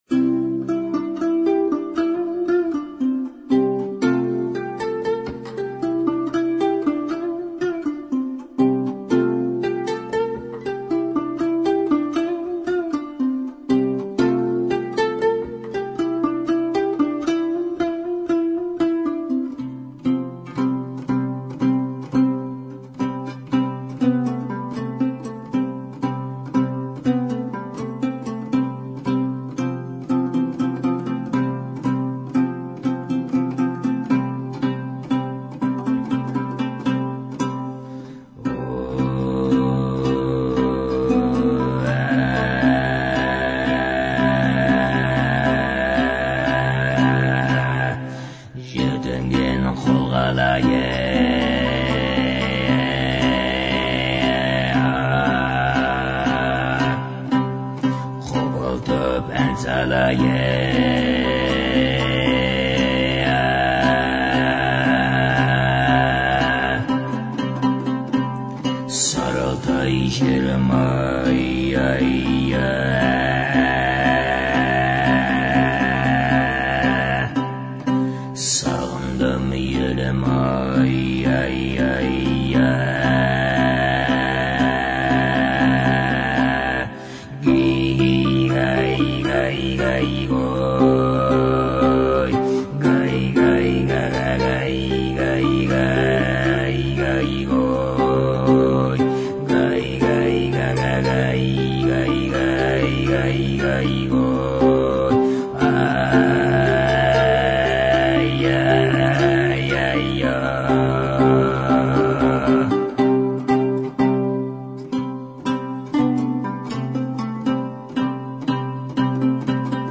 горловое пение, Жетыген